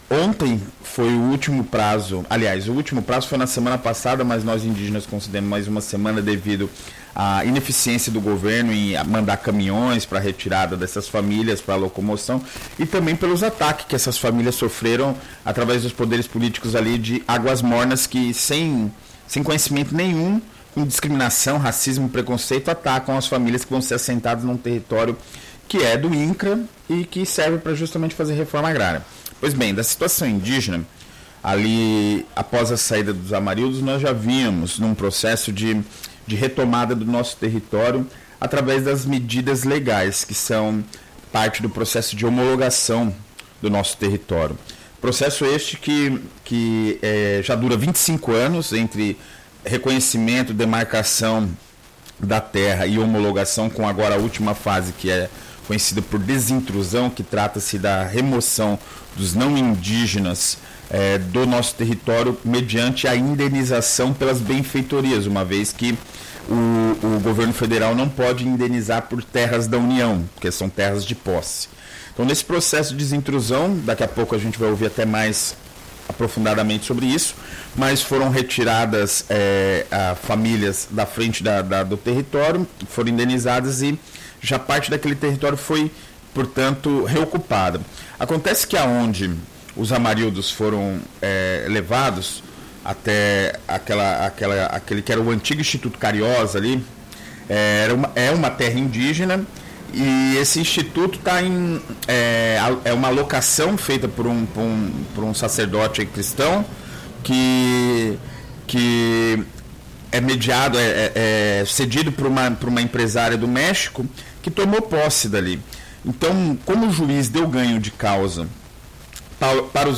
Entrevistas radiofônicas